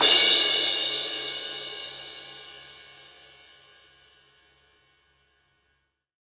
Q 18Crash.WAV